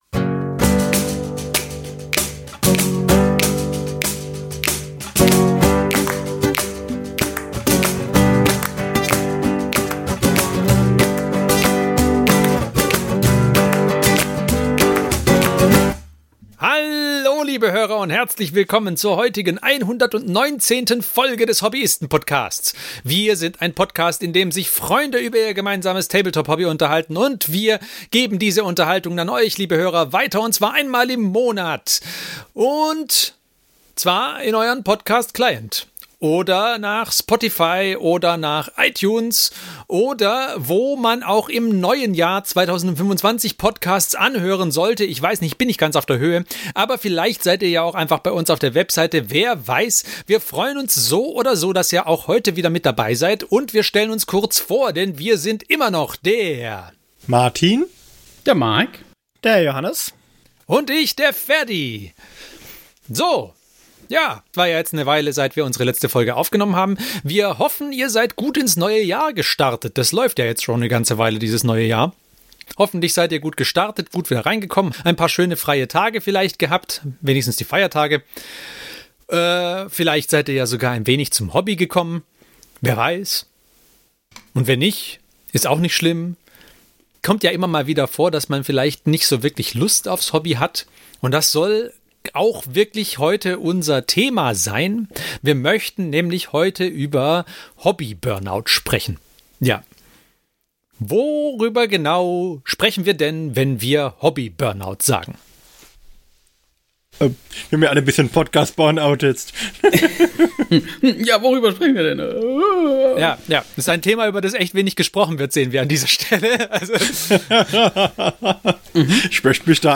Freunde reden über Tabletop